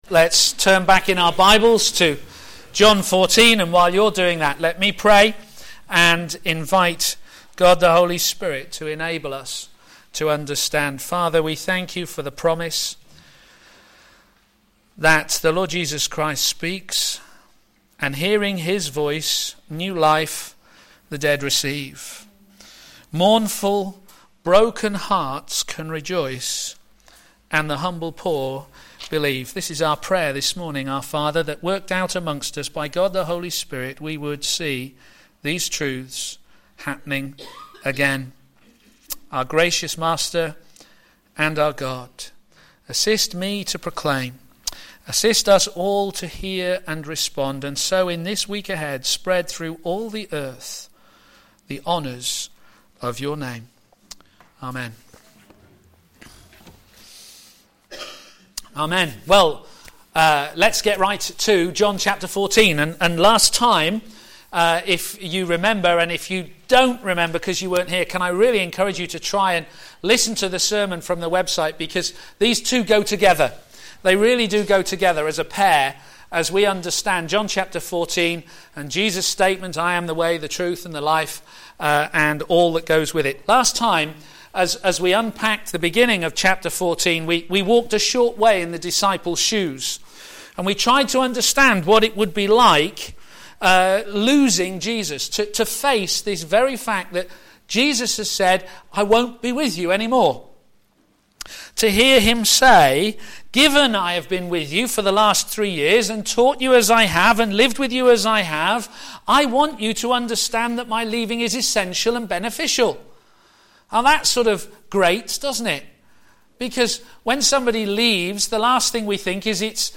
Media for a.m. Service
The Way, The Truth and THe LIfe Sermon